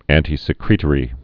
(ăntē-sĭ-krētə-rē, ăntī-)